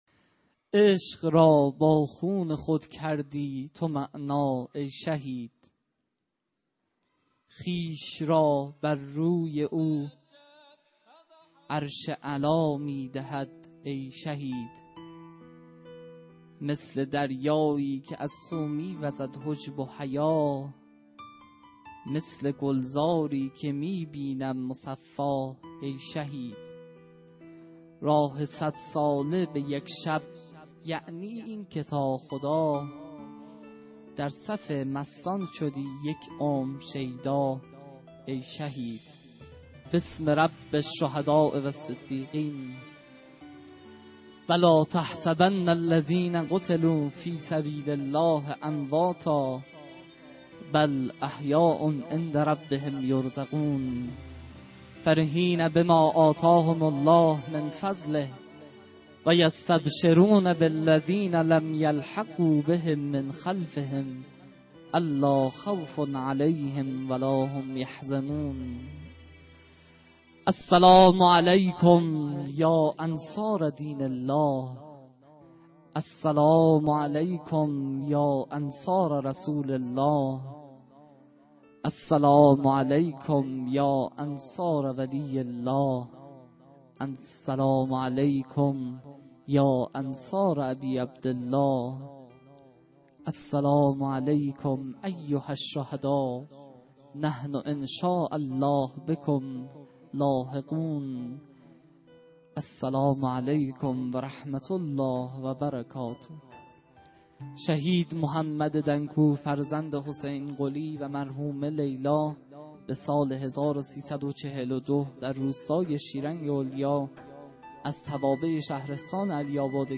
در این بخش می توانید فایل صوتی بخش های مختلف “یکصد و هفتاد و ششمین کرسی تلاوت و تفسیر قرآن کریم” شهرستان علی آباد کتول که در تاریخ ۰۸/خرداد ماه/۱۳۹۷ برگزار شد را دریافت نمایید.